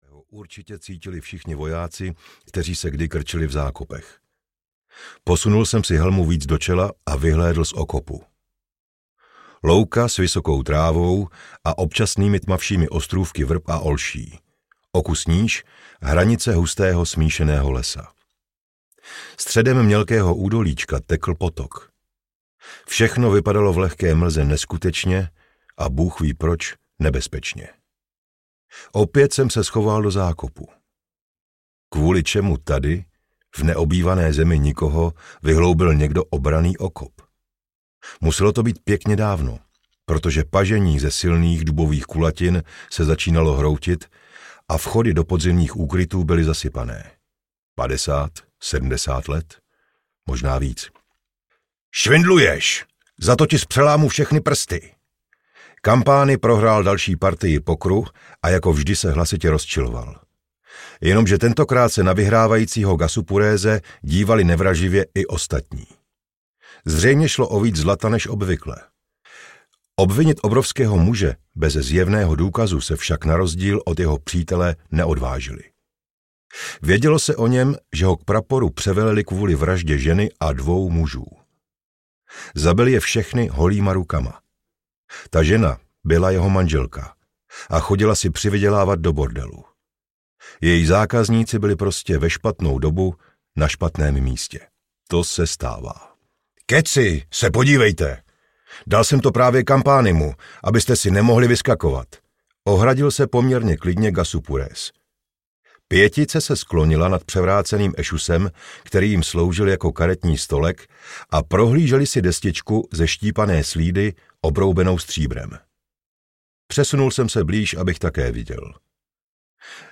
Seržant audiokniha
Ukázka z knihy